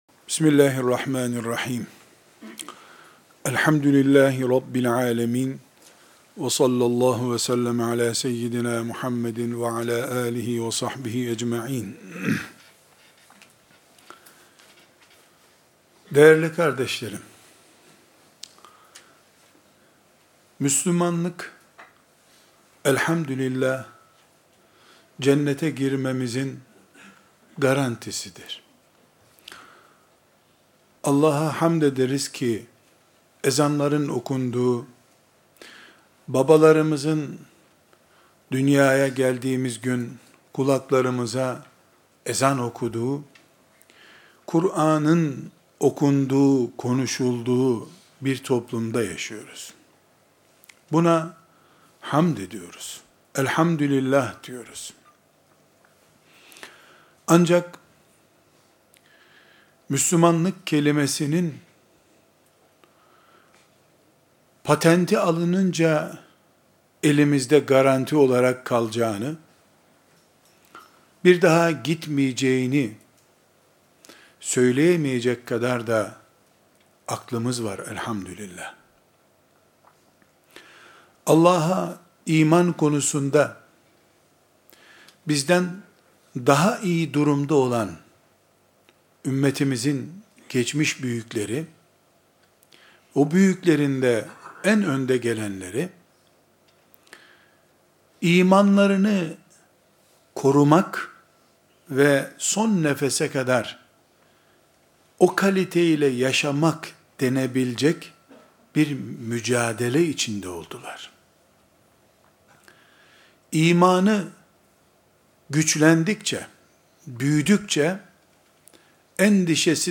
8 Mart 2014 Pazar günü yapılan Hayat Rehberi Sohbetidir.